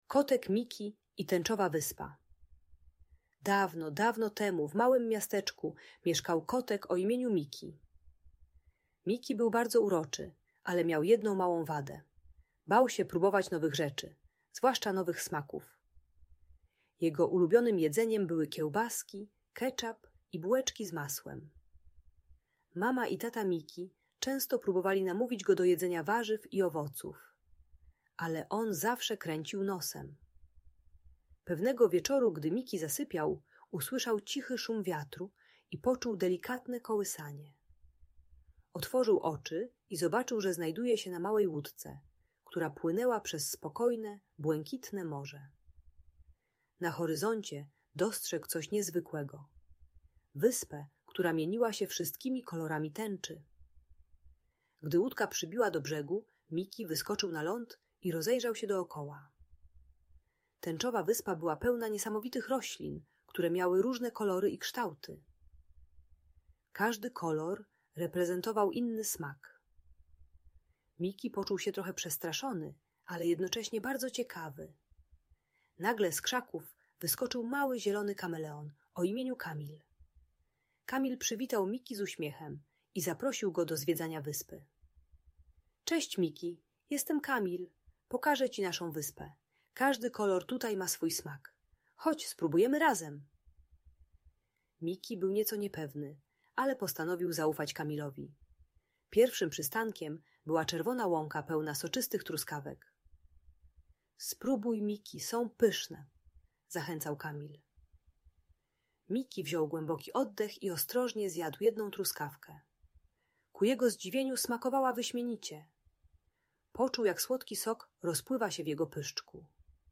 Przygoda kotka Miki na Tęczowej Wyspie - Problemy z jedzeniem | Audiobajka